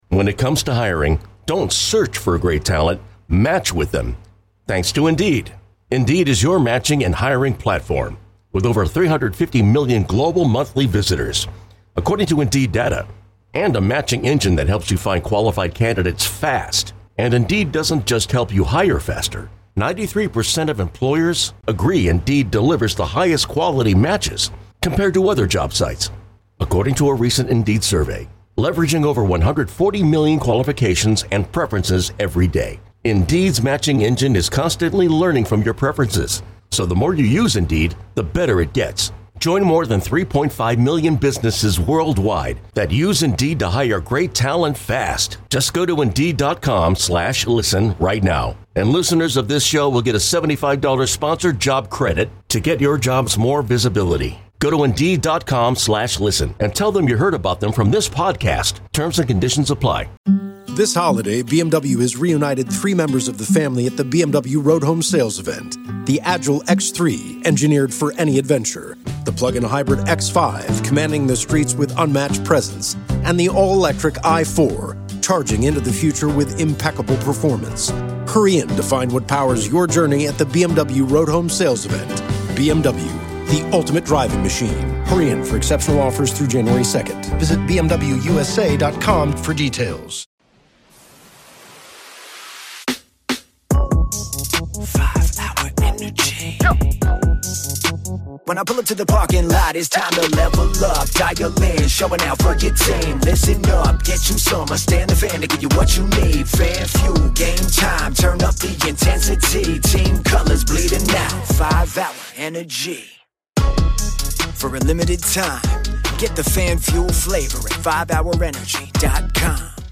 live at Blain's Farm and Fleet in Oak Creek to discuss the Golden Bat Rule and where the Brewers need to go as the off season has gotten under way.